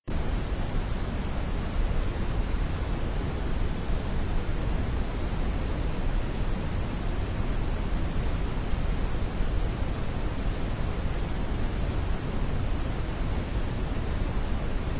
Brownian Noise (even more intensity in the lower frequencies)
BrownianNoise-15-seconds.mp3